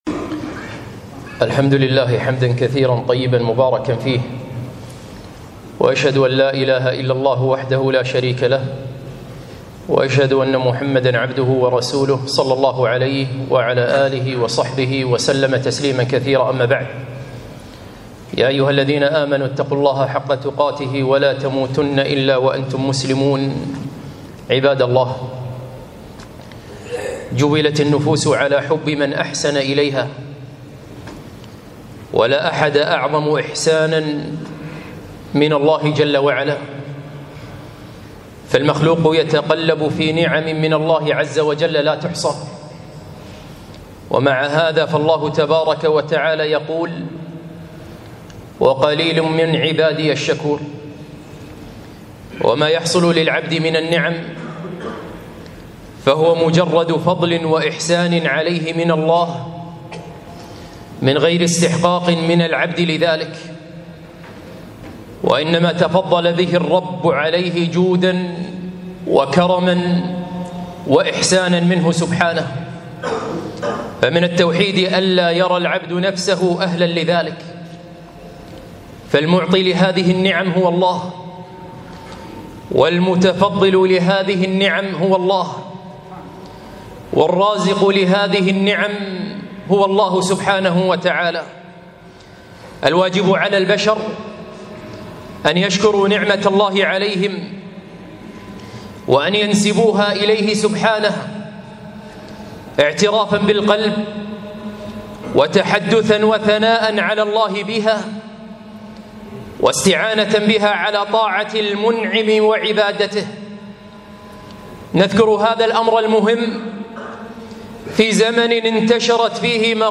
خطبة - هذا كله من ذكائي وفطنتي